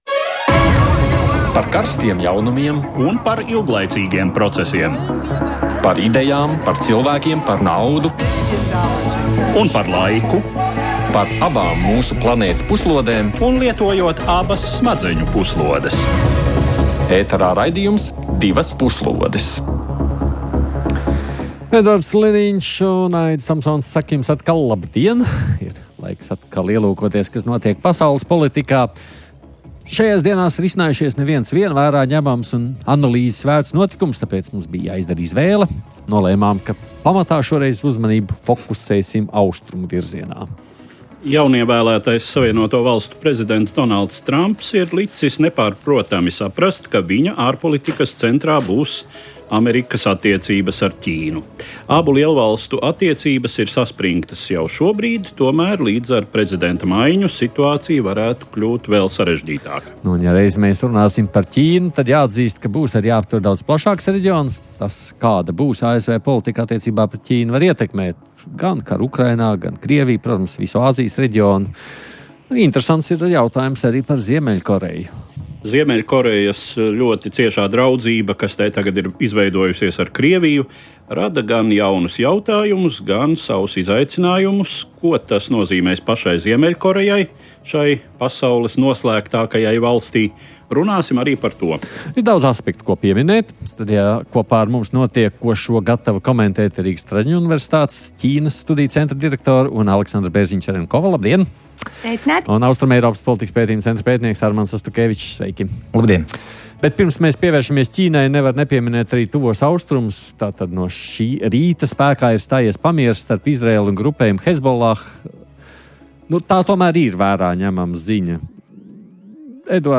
Raidījums par ārpolitikas aktualitātēm, kurā kopā ar ekspertiem un ārpolitikas pārzinātājiem apspriežam un analizējam nedēļas svarīgākos notikumus pasaulē.